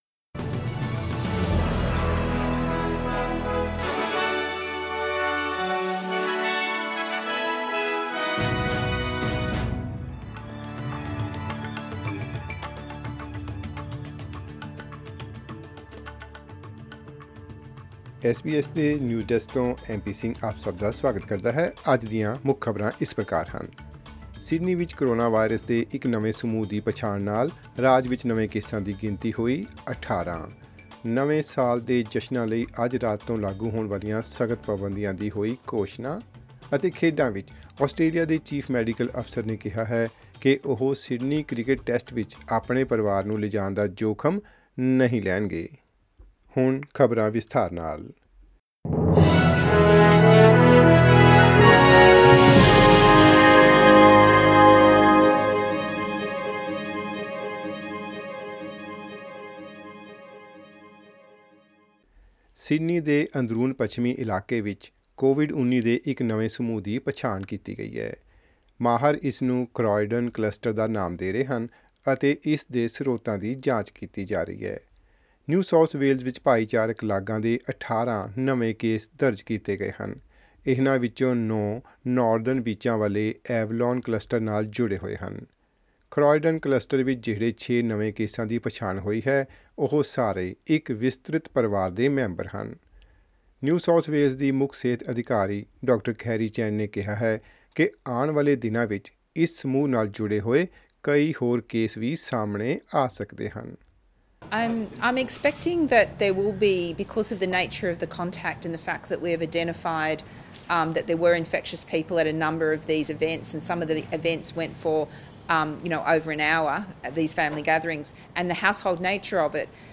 SBS Punjabi News 30 Dec: Another COVID-19 cluster identified in Sydney as the state records 18 new cases